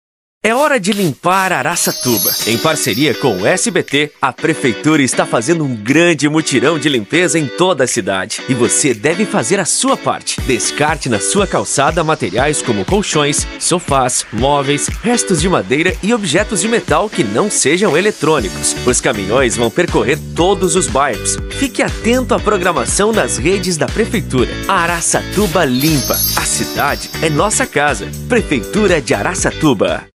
INSTITUCIONAL 02: